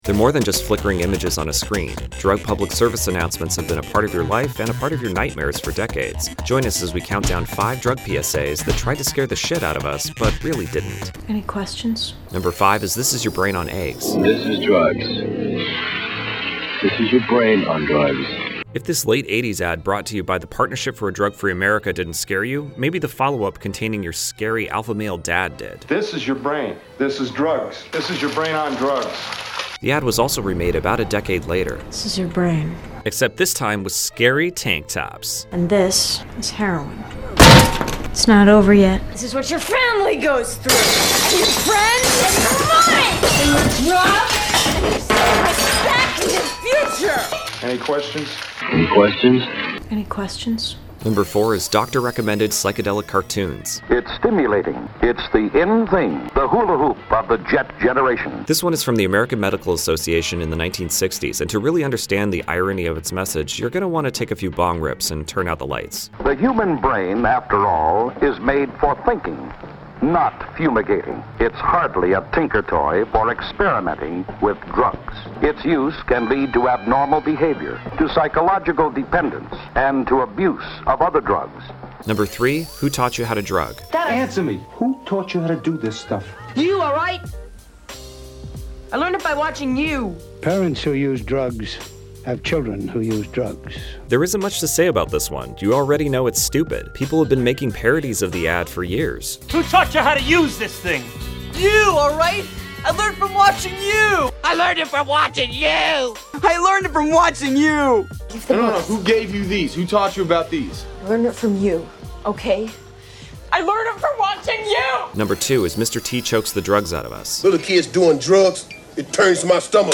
Preachy, hyperbolic, often incorrect